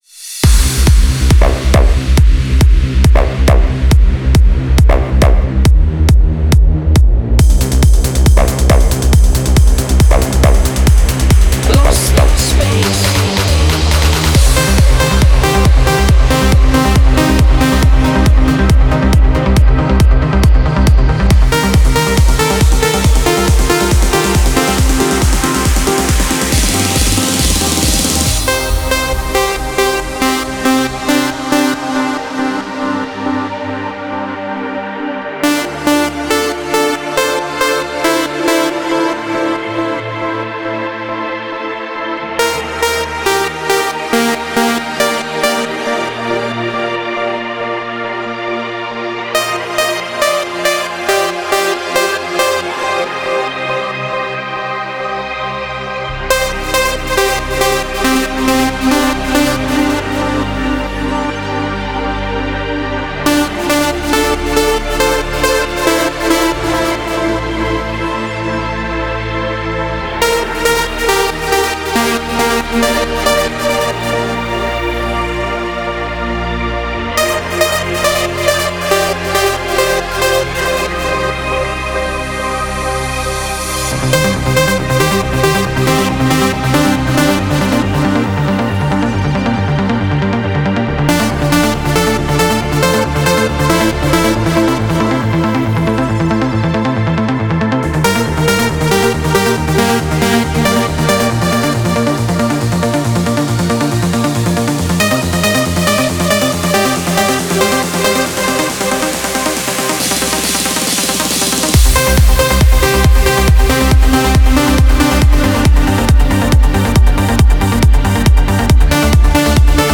это энергичный трек в жанре транса